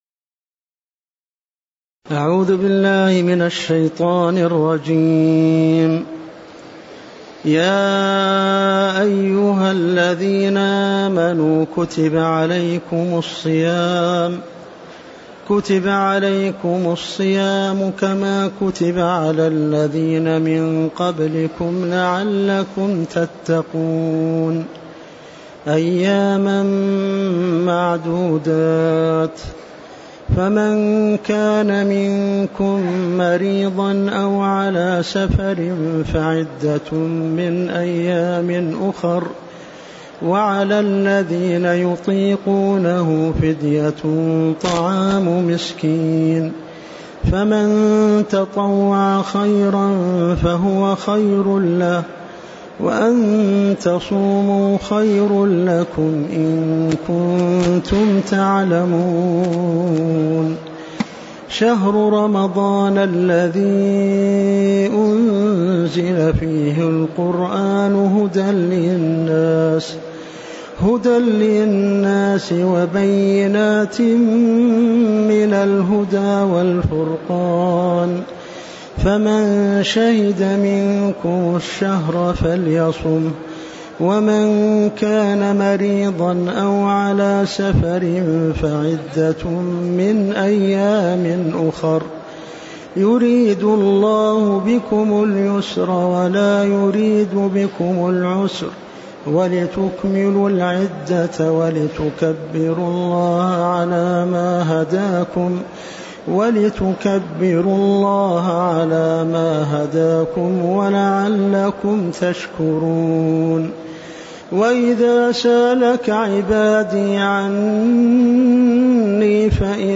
تاريخ النشر ١٣ رمضان ١٤٤٣ المكان: المسجد النبوي الشيخ